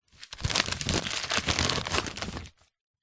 Звук смятой журнальной бумаги